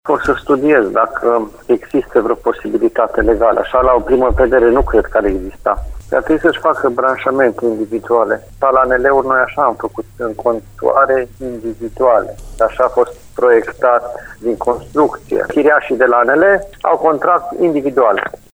Primarul interimar al Lugojului, Bogdan Blidariu, spune că administrația locală nu are cum să-i ajute în momentul de față și că locatarii trebuie să-și facă contracte individuale.